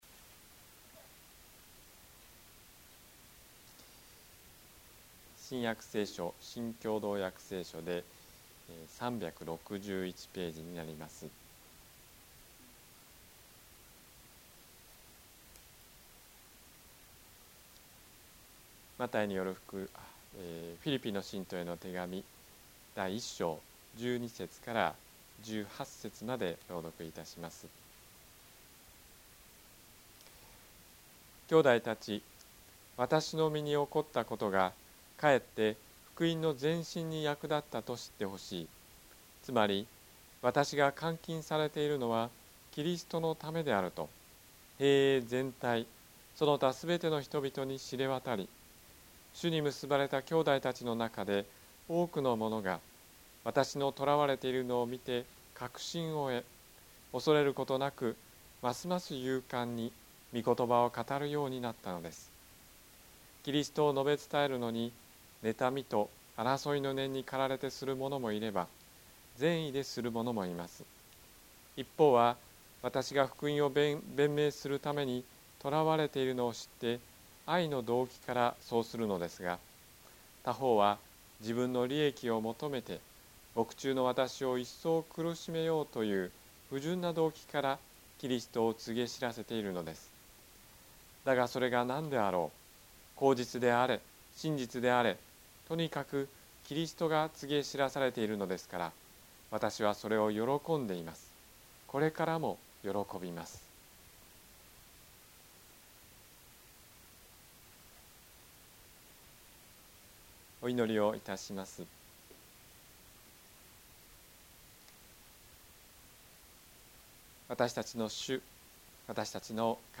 日曜 夕方の礼拝
説教